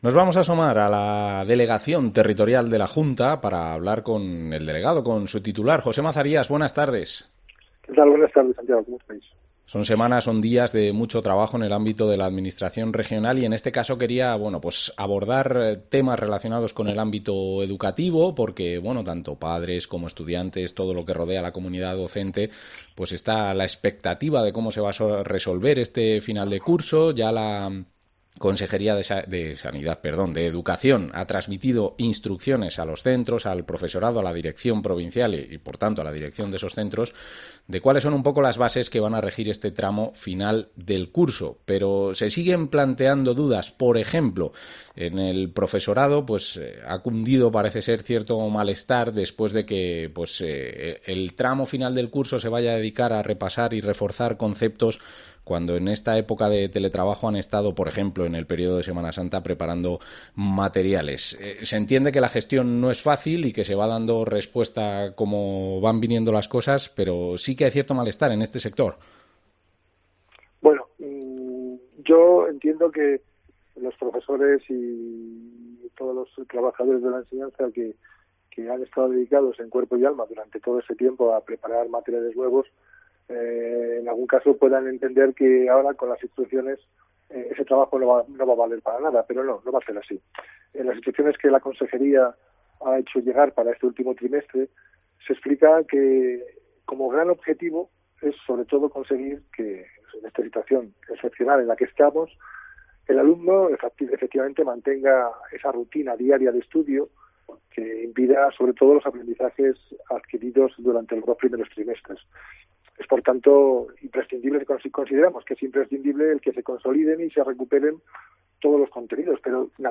Entrevista a José Mazarías, delegado territorial de la Junta de Castilla y León en Segovia